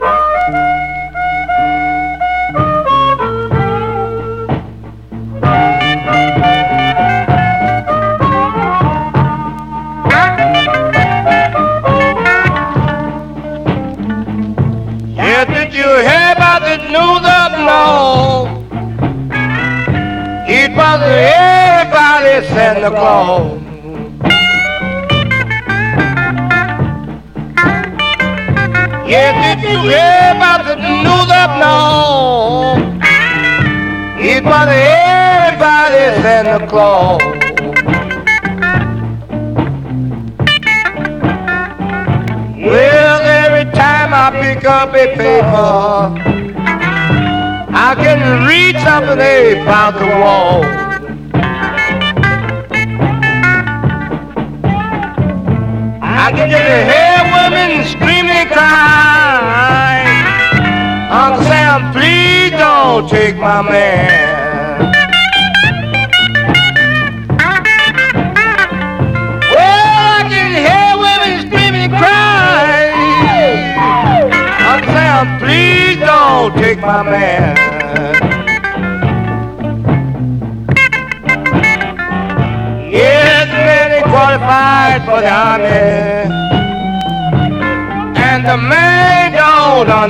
CHICAGO BLUES